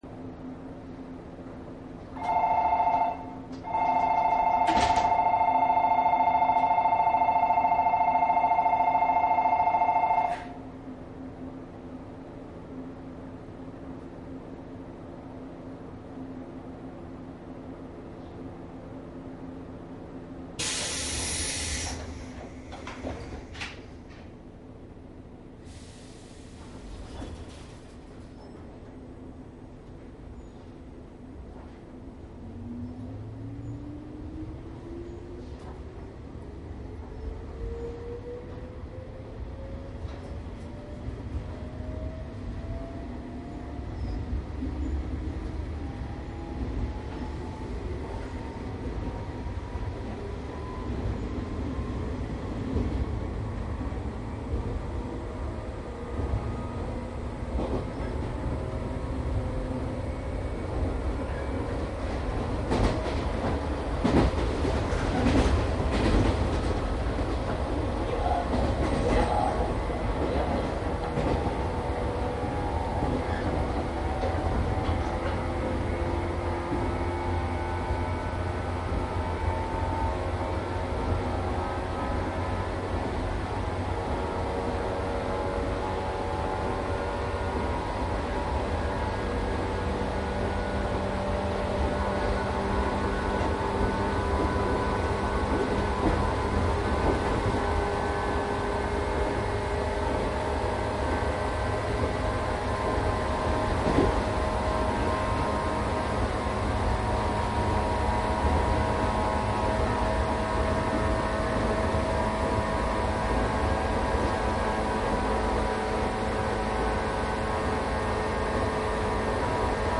宇都宮線 115系 上り  走行音 CD
MDの通常SPモードで録音（マイクＥＣＭ959）で、これを編集ソフトでＣＤに焼いたものです。